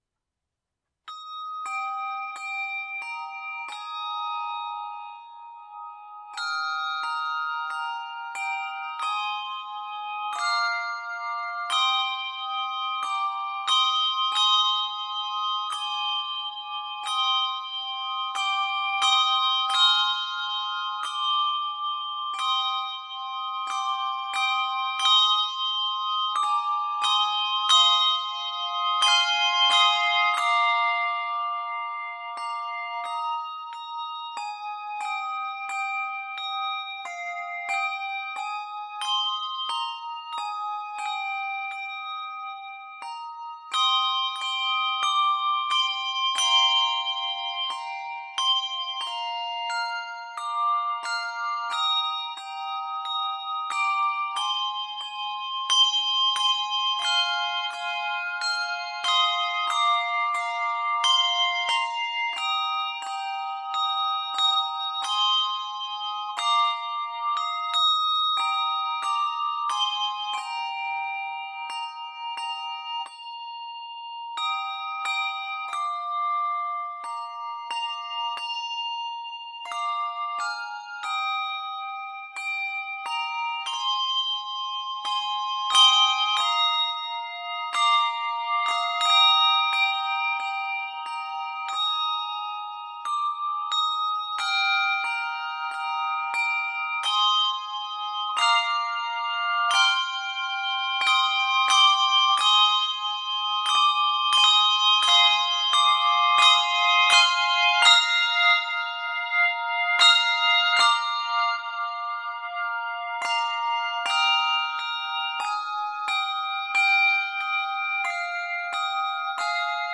This contemplative setting of the hymntune